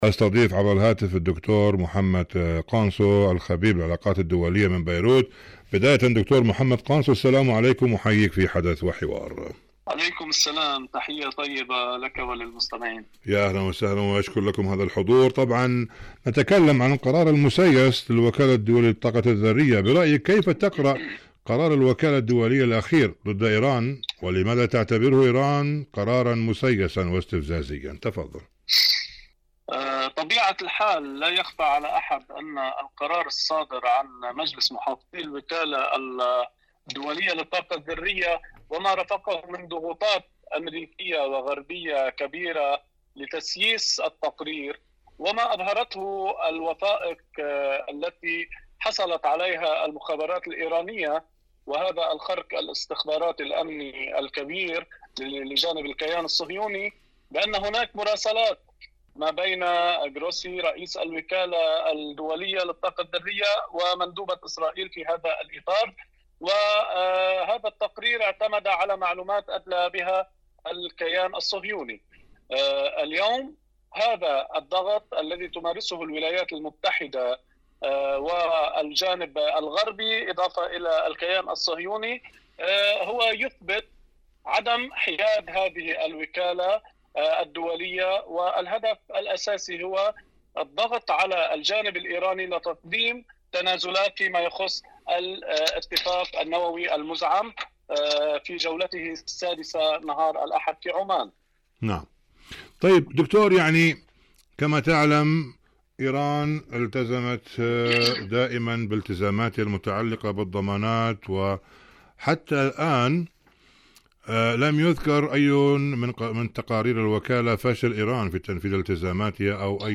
إذاعة طهران- حدث وحوار: مقابلة إذاعية